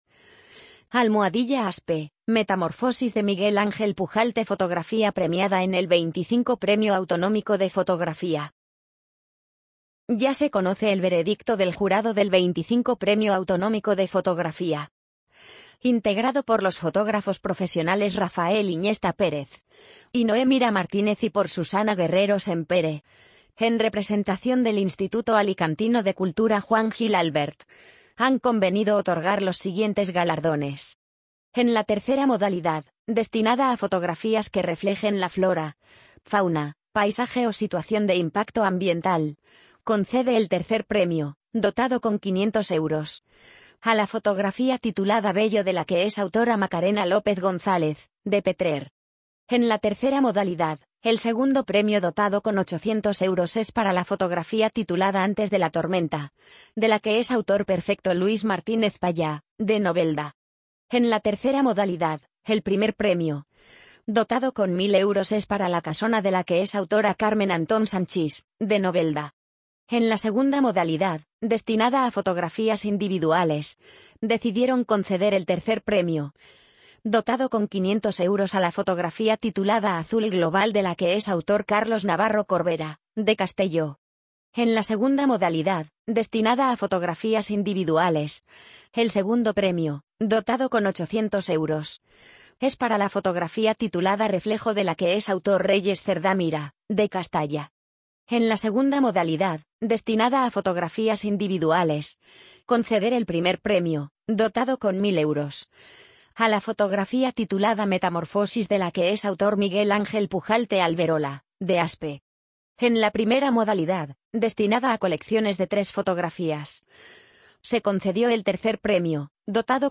amazon_polly_68506.mp3